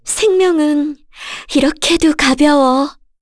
FreyB-Vox_Skill5b_kr.wav